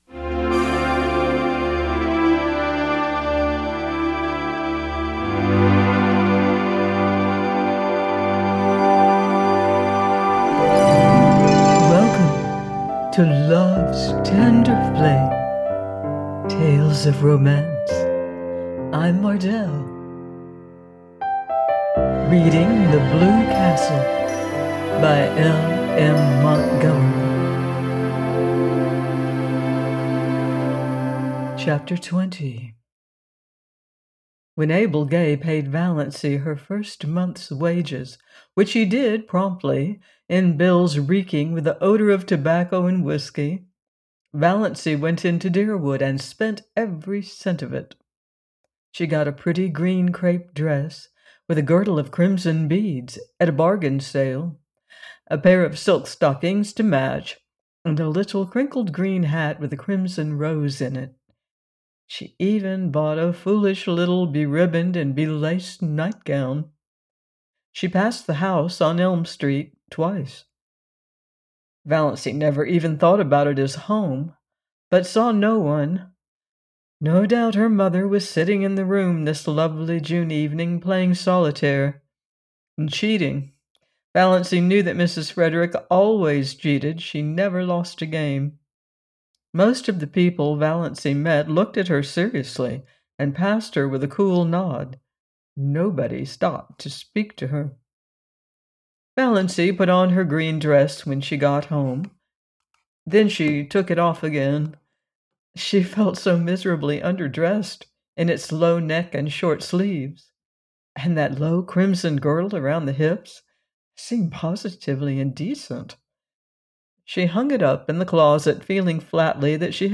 The Blue Castle by L.M. Montgomery - audiobook